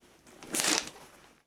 Correr unas cortinas 2
Sonidos: Acciones humanas Sonidos: Hogar